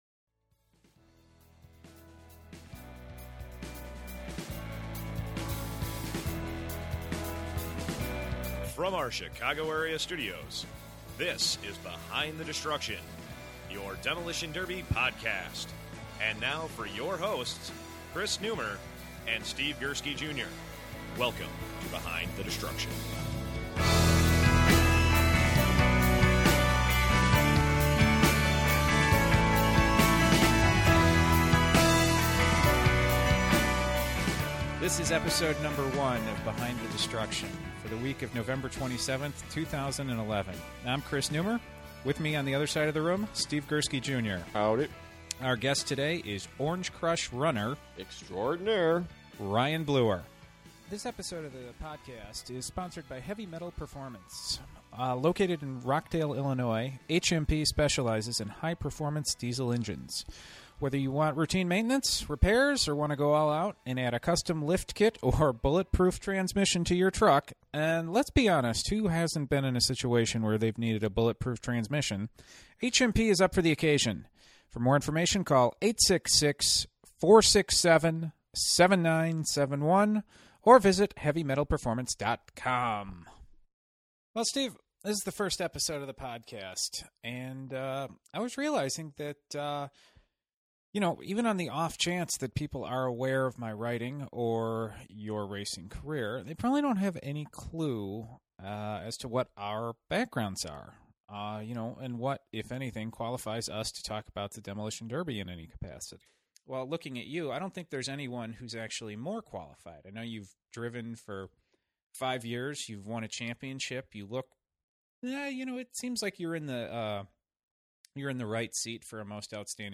interview
you’ll notice a little bit of a background hiss
is soft-spoken
the hiss is due to the fact that we increased the volume on his recorded track in post-production.